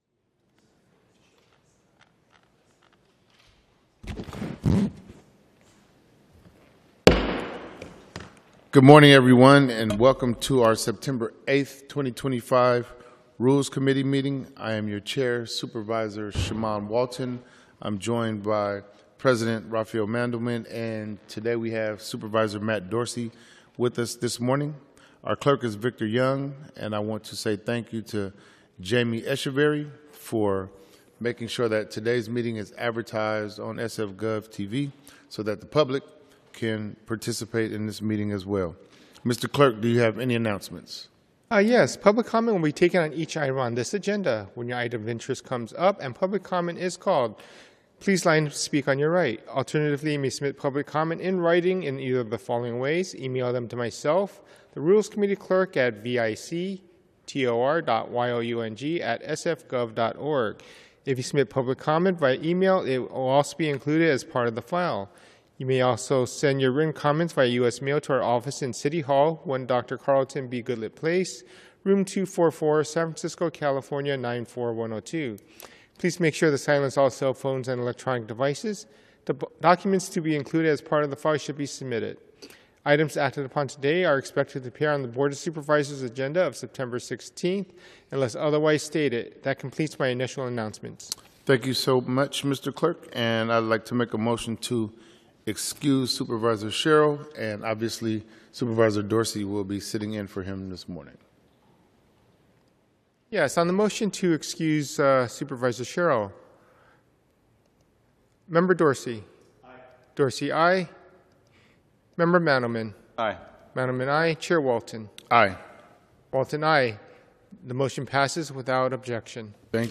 Rules Committee - Regular Meeting - Sep 08, 2025